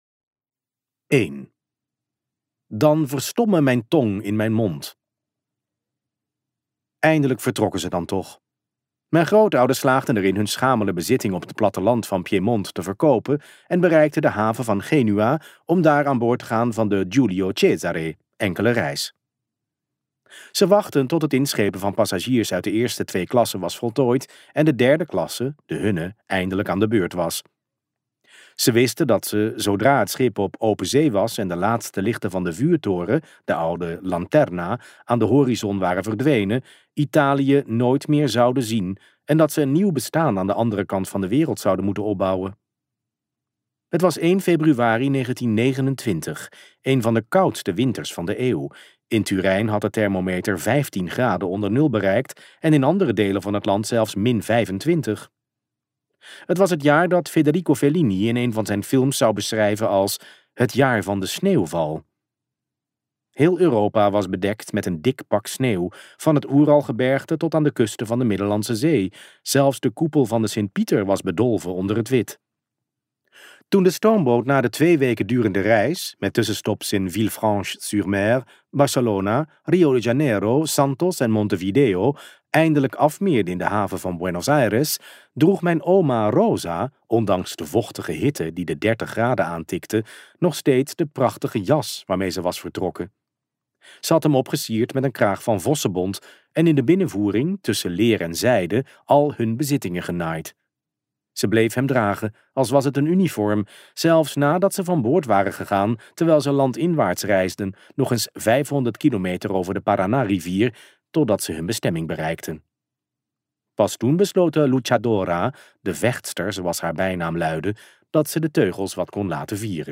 Ambo|Anthos uitgevers - Hoop luisterboek 2